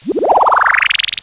scifi20.wav